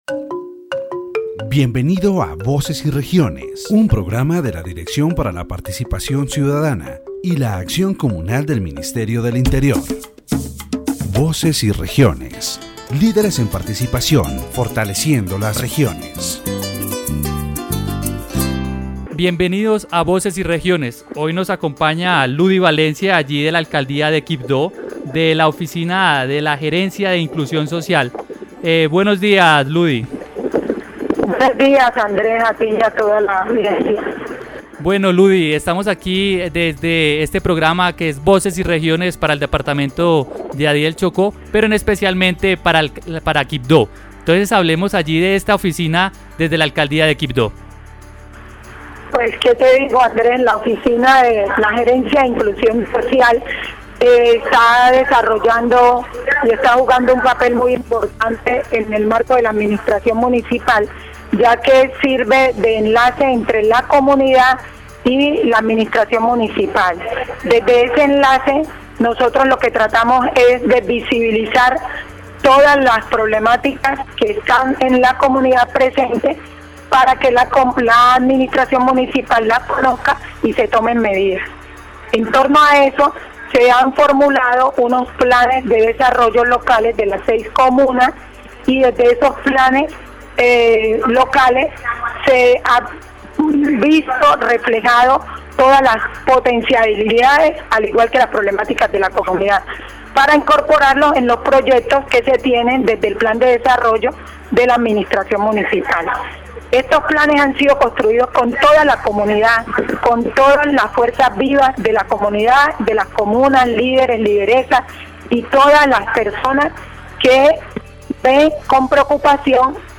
The radio program focuses on the social inclusion initiatives that are being carried out in Quibdó, Chocó.